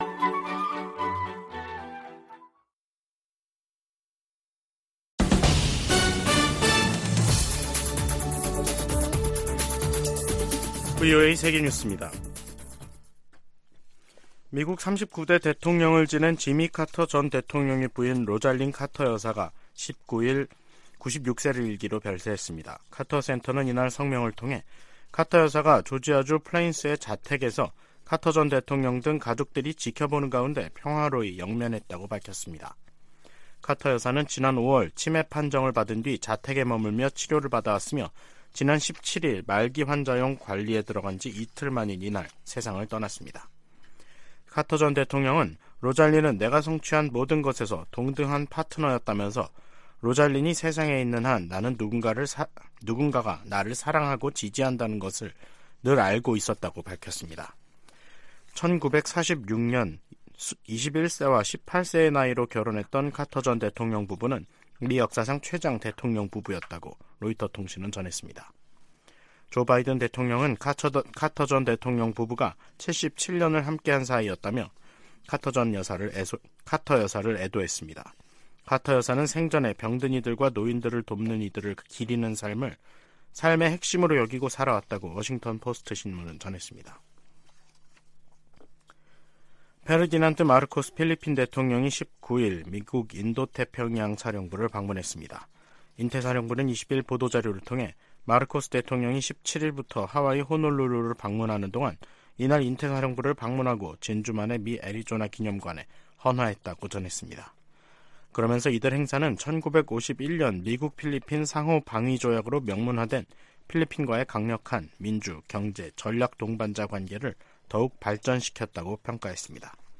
VOA 한국어 간판 뉴스 프로그램 '뉴스 투데이', 2023년 11월 20일 3부 방송입니다. 아시아태평양경제협력체(APEC) 21개 회원국들이 다자무역의 중요성을 강조하는 ‘2023 골든게이트 선언’을 채택했습니다. 한국 합동참모본부는 북한에 3차 군사정찰위성 발사 준비를 중단하라는 경고성명을 냈습니다. 미중 정상회담으로 두 나라간 긴장이 다소 완화된 것은 한반도 정세 안정에도 긍정적이라고 전문가들이 진단했습니다.